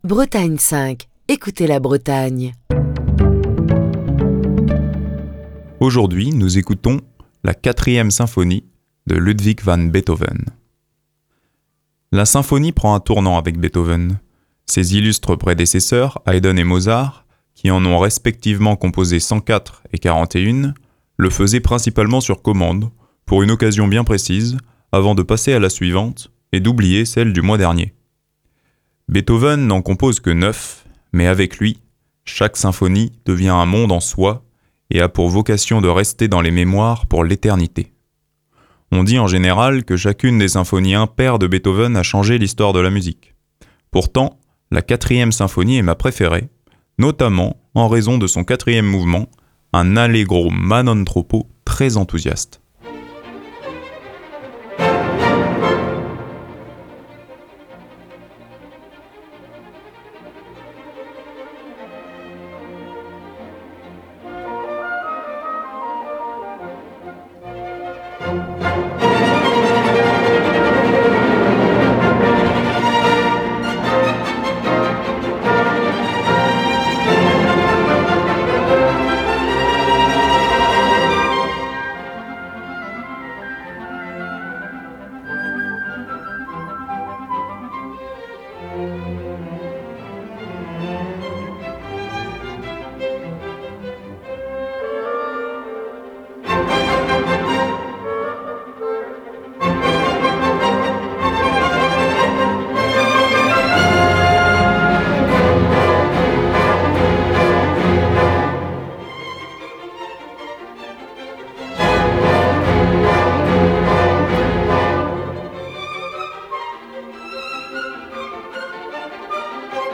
en si bémol majeur